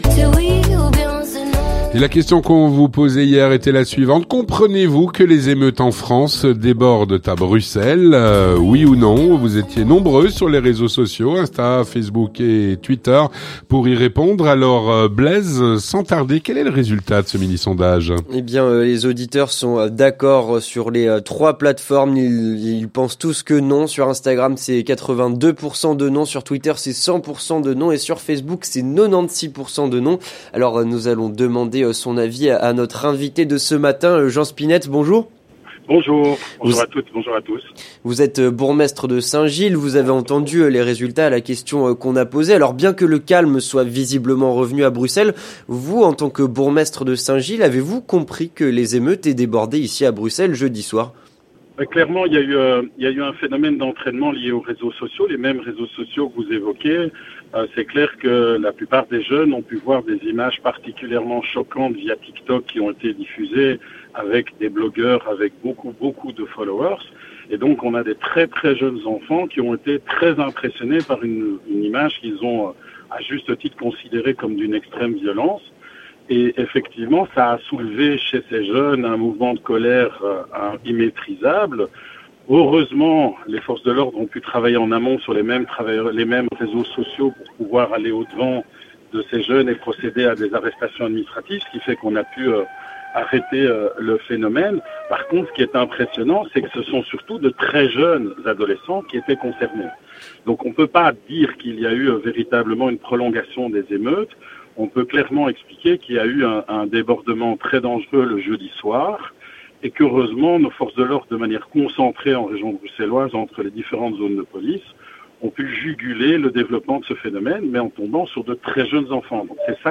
Avec Jean Spinette, bourgmestre de St-Gilles